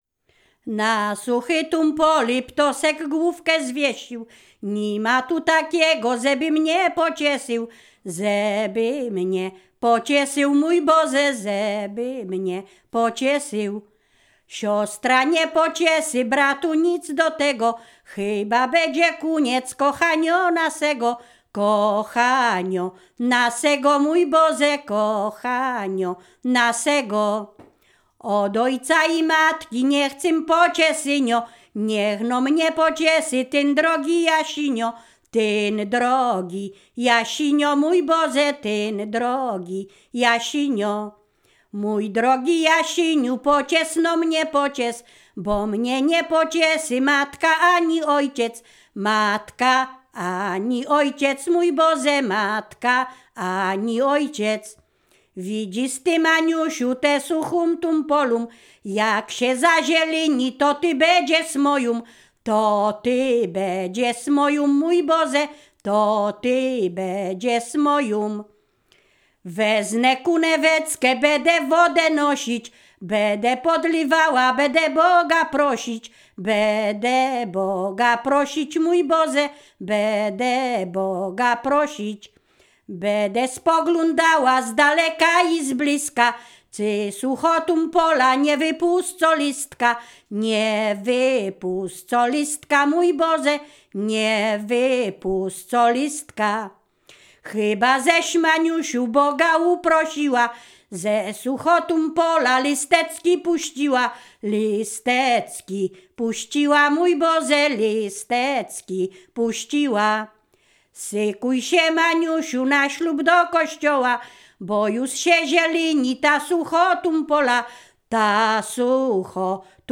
Ziemia Radomska
liryczne miłosne weselne